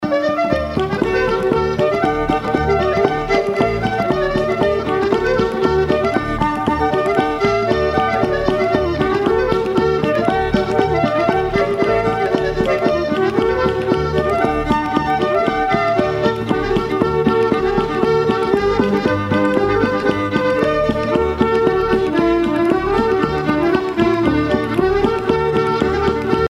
danse : reel
Pièce musicale éditée